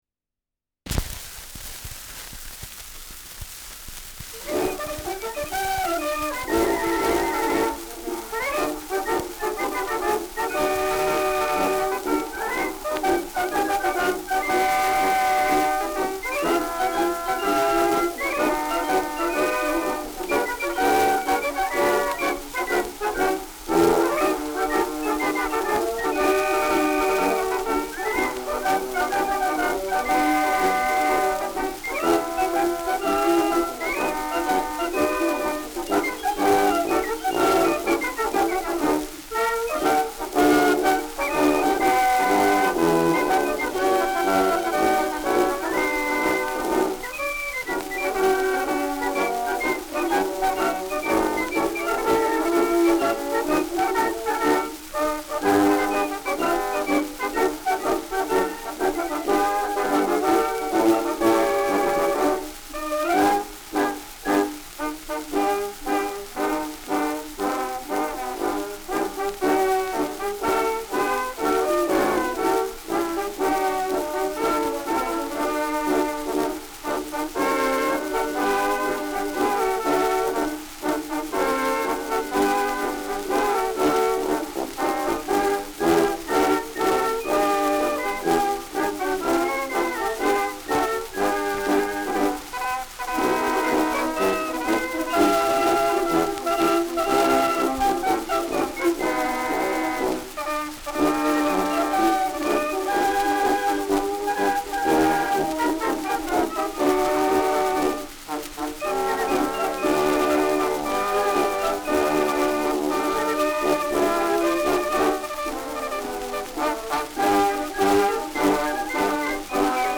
Schellackplatte
Stärkeres Rauschen
Militärmusik des 80. Preußischen Infanterie-Regiments, Wiesbaden (Interpretation)